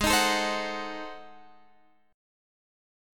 G#7b9 chord